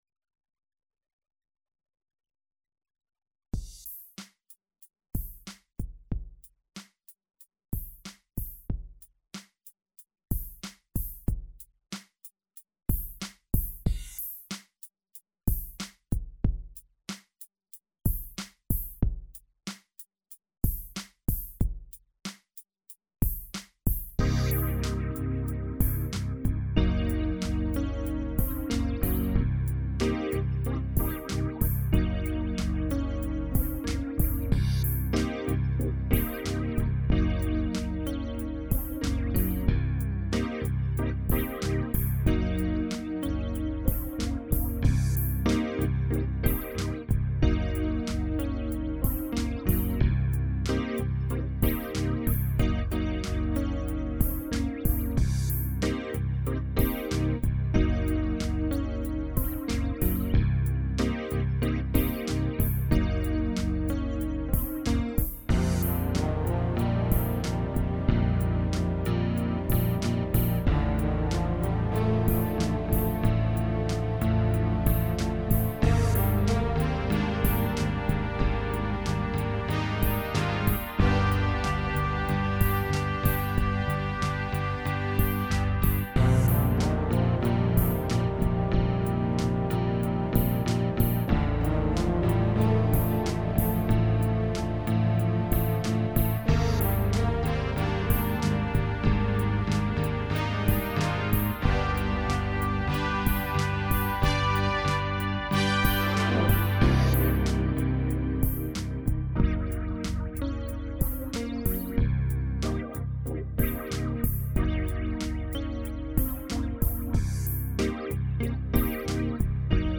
7:02/93bpm
Completely performed on a Kurzweil keyboard